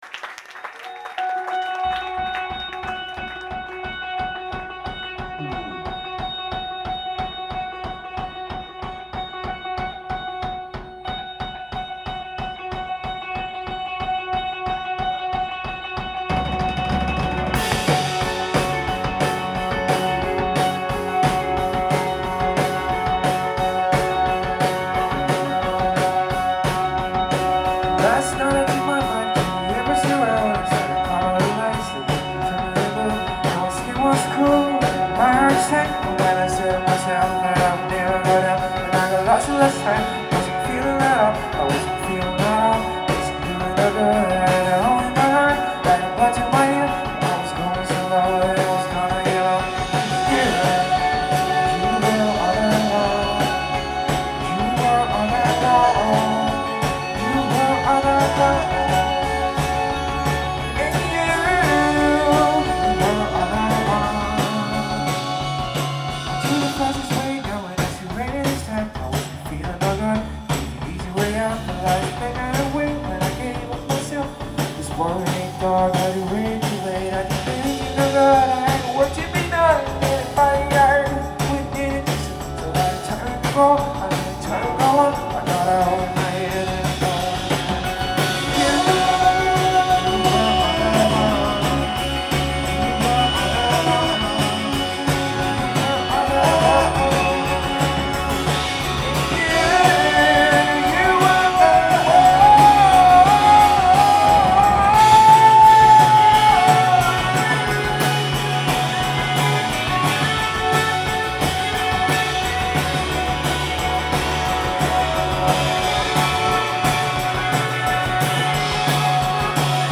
Live at Great Scott